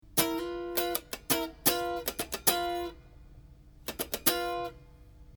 ３連符に注意
今回のフレーズで特に難しいのが３連符のブラッシングです。